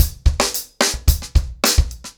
TimeToRun-110BPM.7.wav